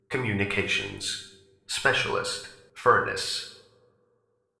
scientist16.ogg